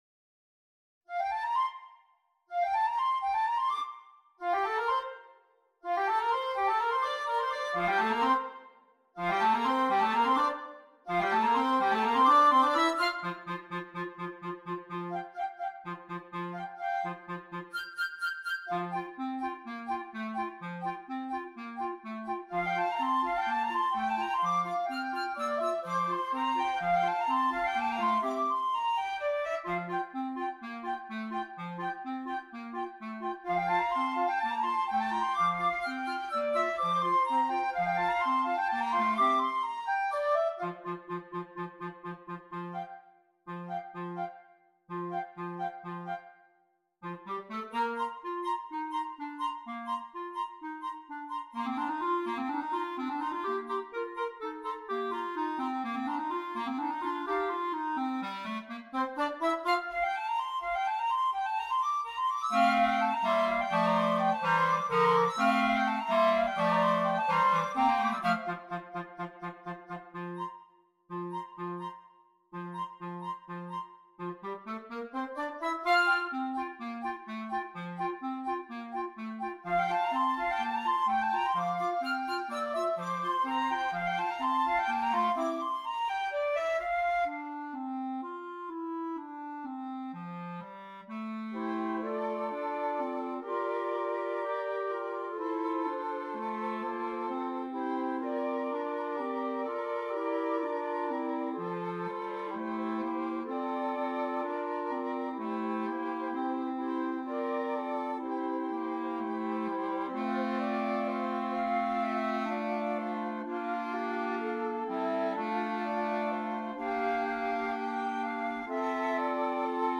Voicing: 2 Flute and 2 Clarinet